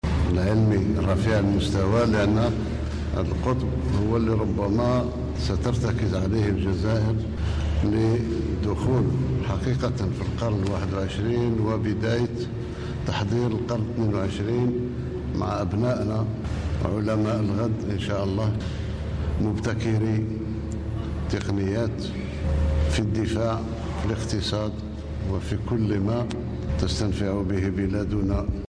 22 مايو 2024 برامج إذاعية 0
و في كلمة له خلال إشرافه على مراسيم الاحتفال باليوم الوطني للطالب الذي جاء تحت شعار ” الطالب الجزائري.. من المقاومة و التحرر الى العلم و التميز” أكد رئيس الجمهورية أن الأرقام المحققة في قطاع التعليم العالي و البحث العلمي دليل على الخطوات العملاقة التي خطتها الجزائر في هذا المجال ،داعيا الشباب و الطلبة الى مواصلة مسيرة الارتقاء بالجزائر الى مصاف الدول الراقية في ميدان البحثي العلمي و تسخيره لحل المشكلات الطبية و الاقتصادية و الفلاحية.
رئيس-الجمهورية-السيد-عبد-المجيد-تبون.mp3